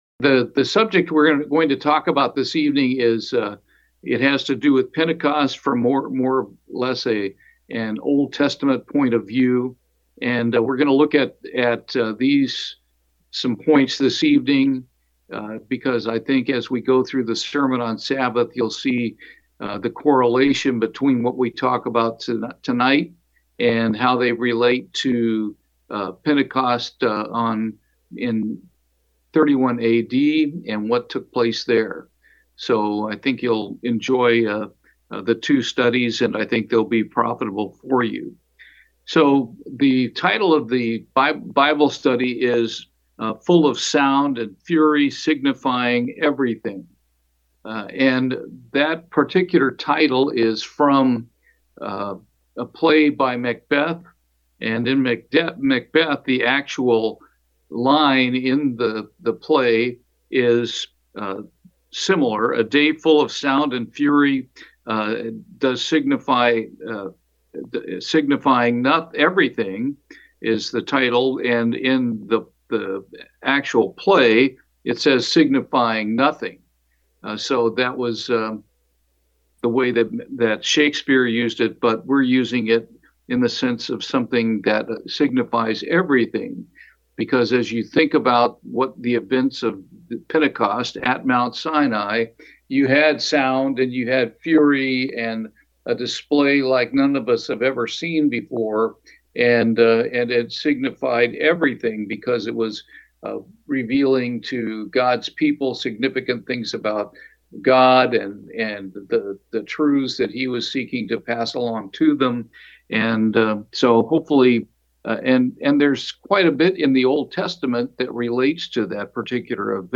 Bible Study, A Day of Sound and Fury